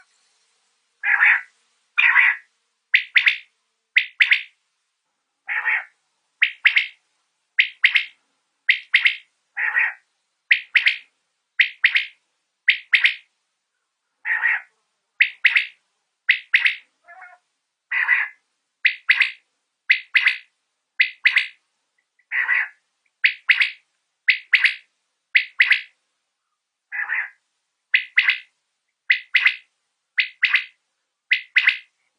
鹌鹑叫声